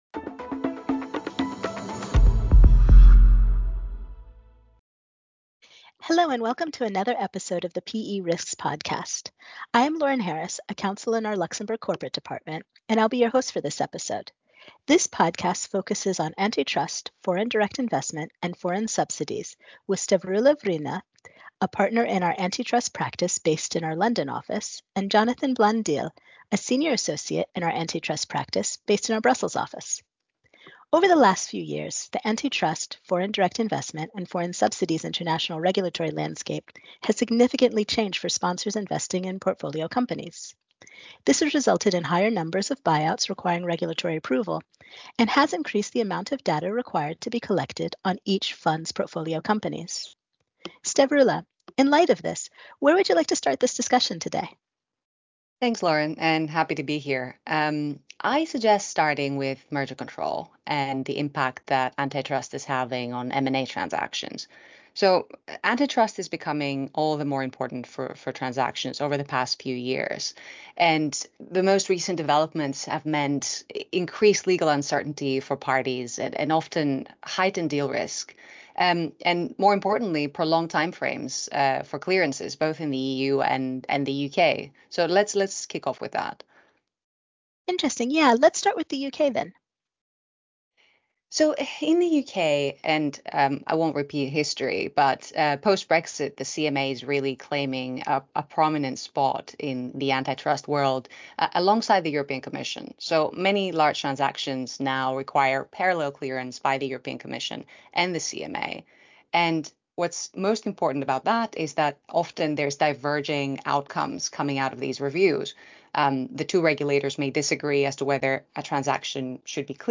hosts a discussion